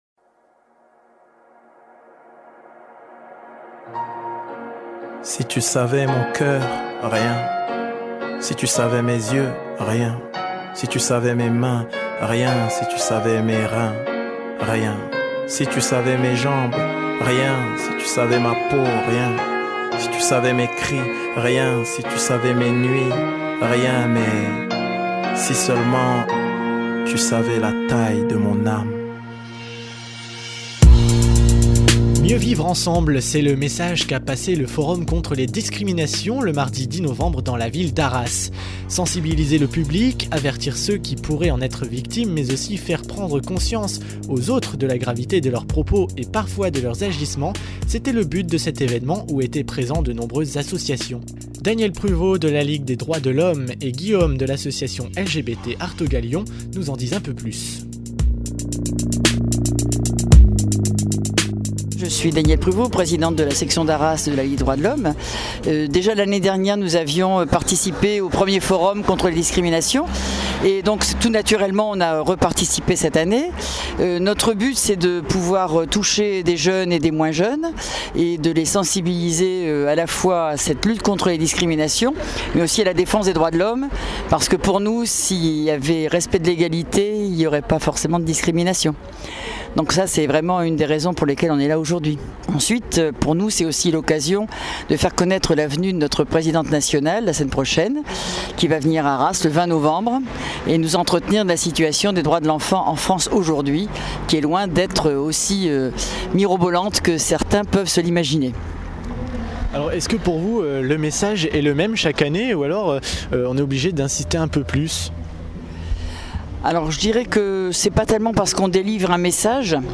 Le 10 novembre à Arras salle Léo Lagrange, se déroulait un forum pour lutter contre les discriminations.
REPORTAGE FORUM DISCRIMINATIONS OK.mp3